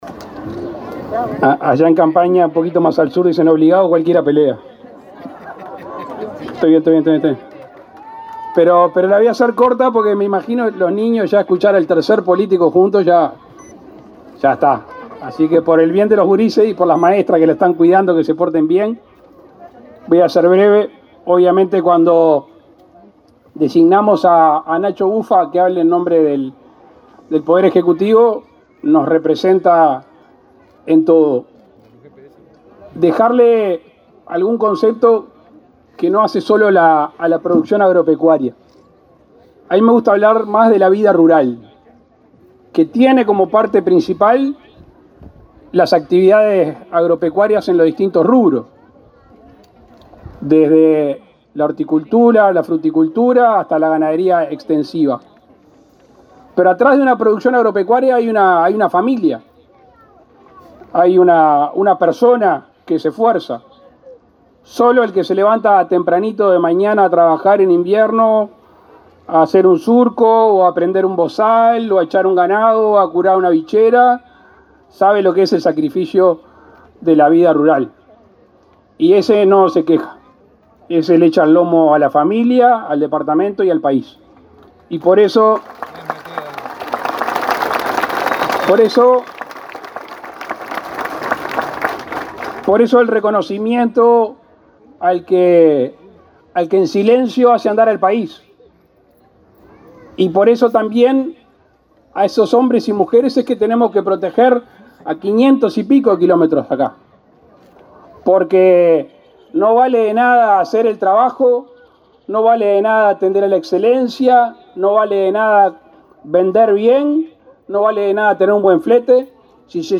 Palabras del presidente de la República, Luis Lacalle Pou
El presidente Lacalle Pou participó, este 30 de setiembre, en la Expo Salto.